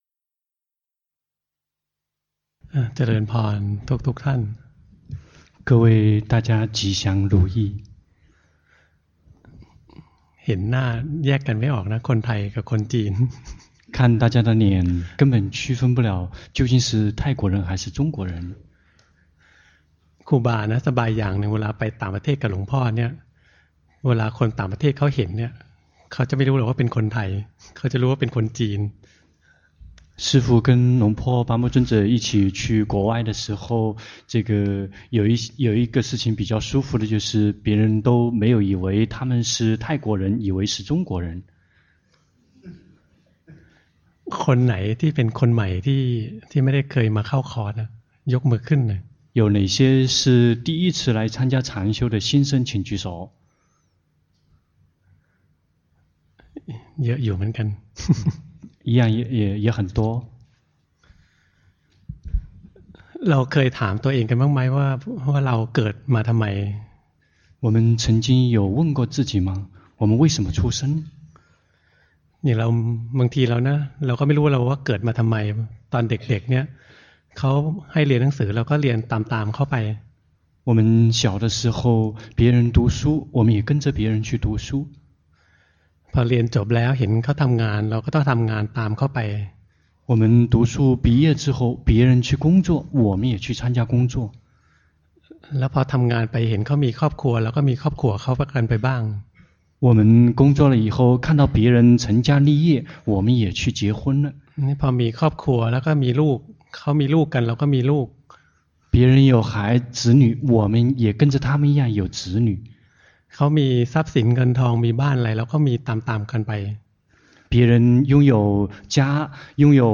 長篇法談｜生之苦楚，出口何處？